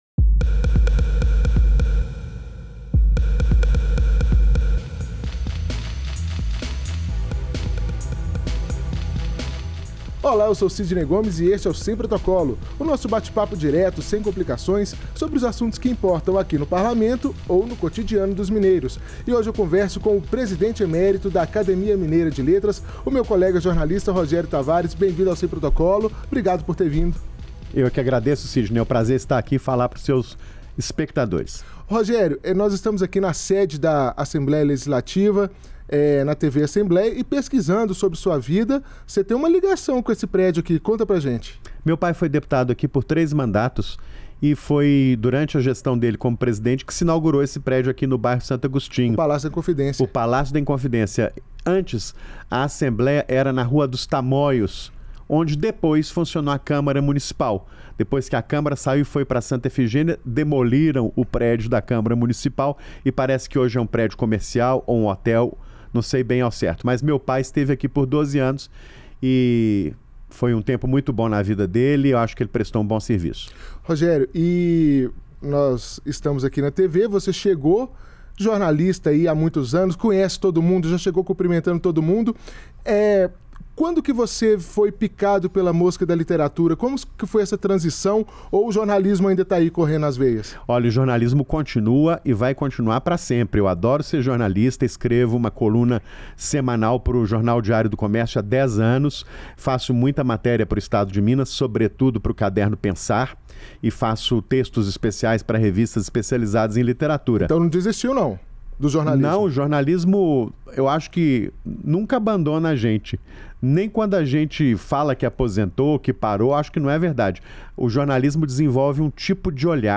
Trinta minutos é pouco tempo para ouvir o jornalista e escritor falar sobre literatura e a contribuição dos mineiros para o cânone brasileiro.